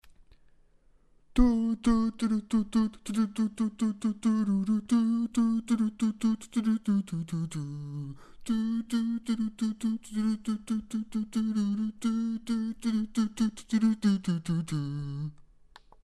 Jediné, co znám, je melodie té písně, přikládám ji v souboru v příloze.